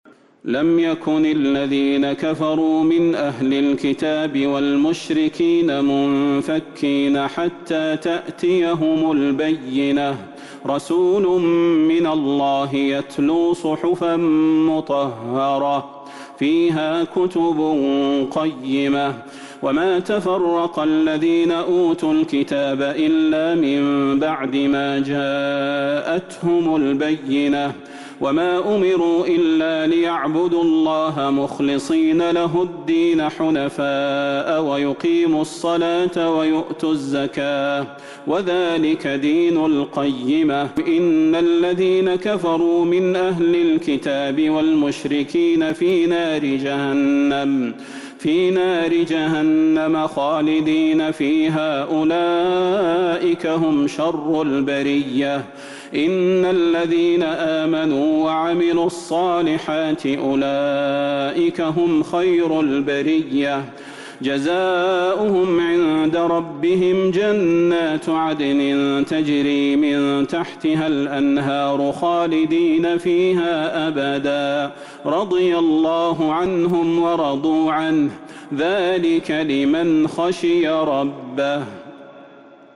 سورة البينة Surat Al-Bayyinah من تراويح المسجد النبوي 1442هـ > مصحف تراويح الحرم النبوي عام 1442هـ > المصحف - تلاوات الحرمين